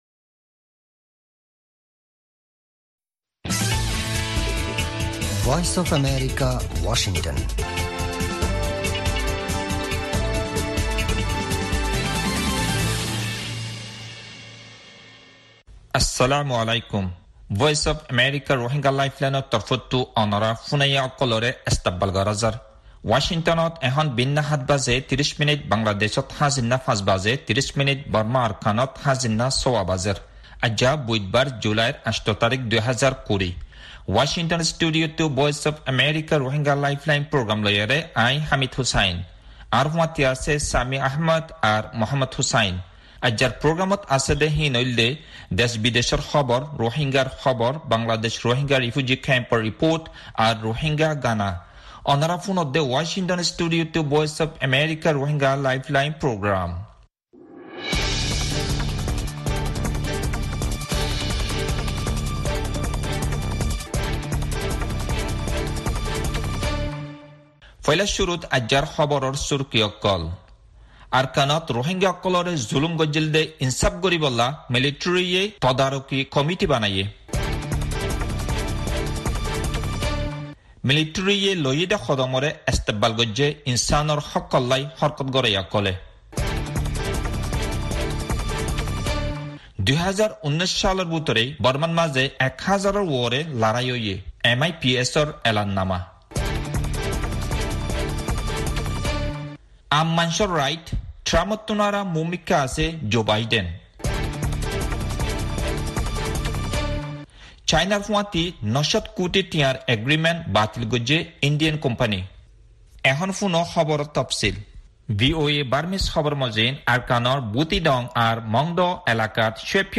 Military formed Court of Inquiry to Take Actions on Alleged Atrocities in Rakhine State, Myanmar (with actualities)